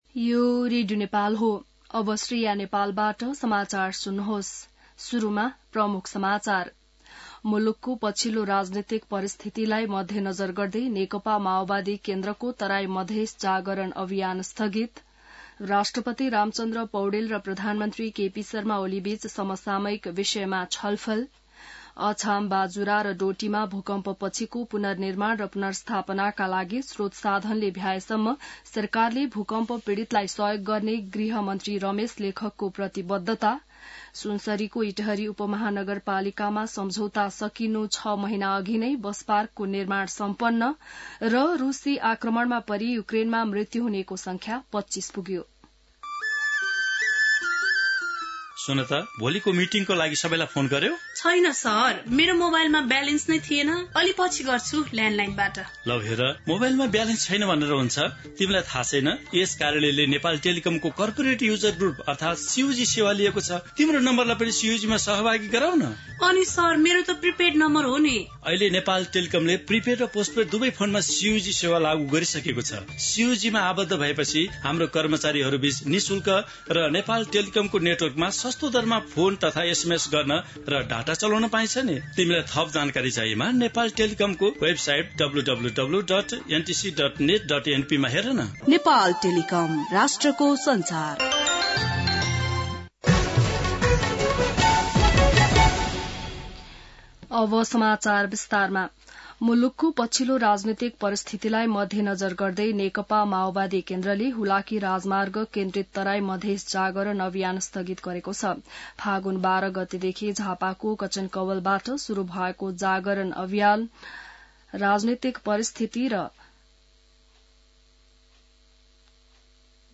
बिहान ७ बजेको नेपाली समाचार : २६ फागुन , २०८१